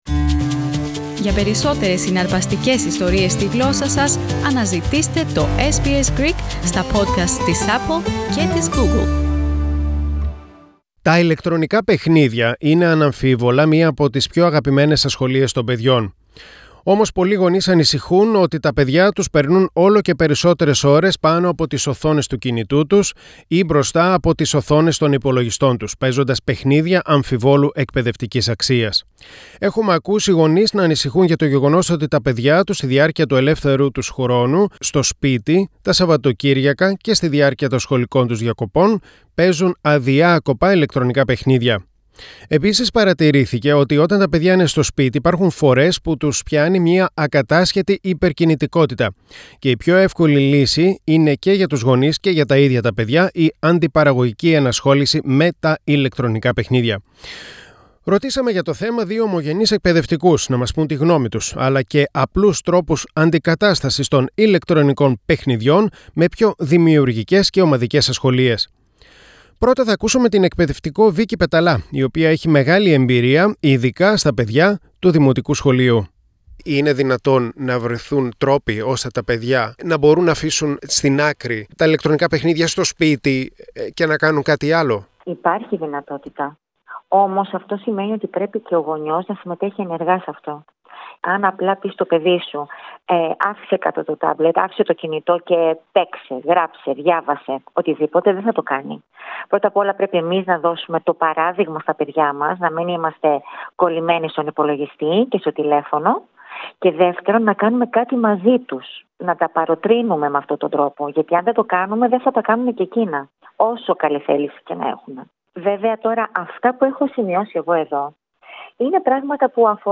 Αξιοποιήστε τα ενδιαφέροντά τους ως πηγές γνώσης Ρωτήσαμε δυο ομογενείς εκπαιδευτικούς να μας πουν την γνώμη τους, αλλά και απλούς τρόπους αντικατάστασης των ηλεκτρονικών παιχνιδιών με πιο δημιουργικές και ομαδικές ασχολίες.